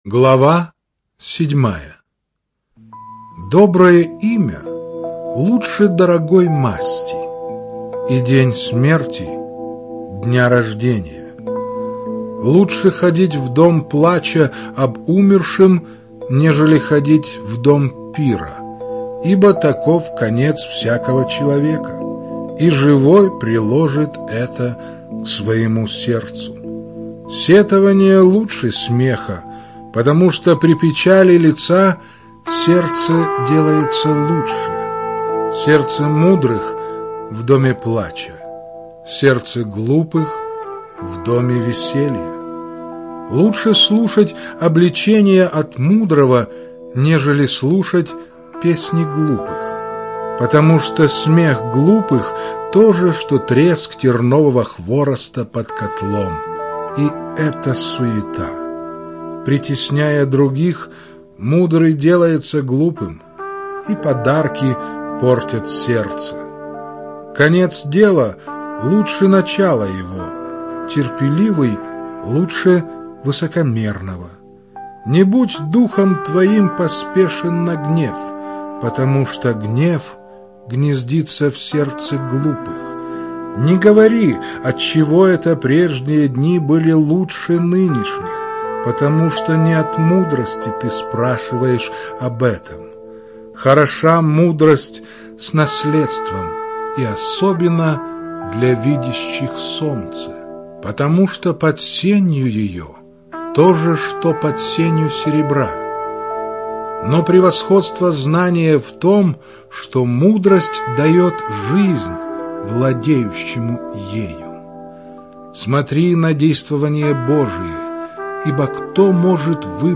Жанр: Аудиокнига
Чтение сопровождается оригинальной музыкой и стерео-эффектами.